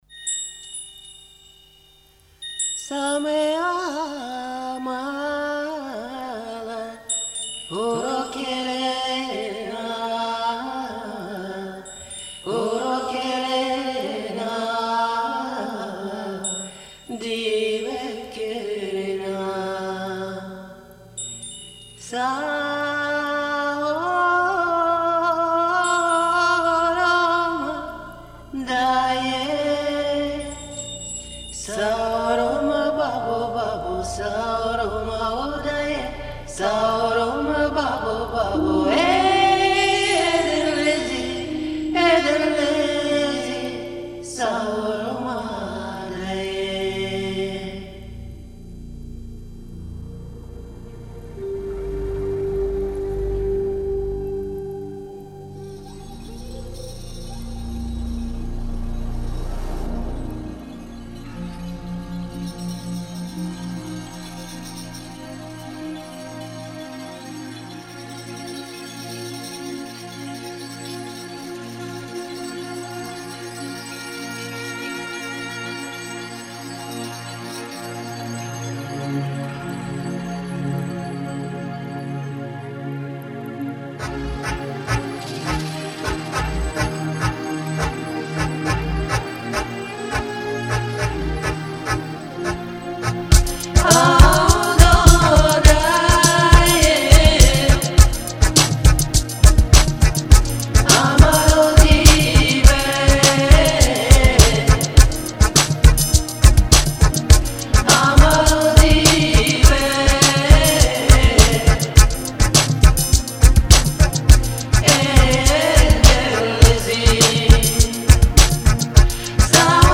тоже я же... дуэтом)))
песня балканских цыган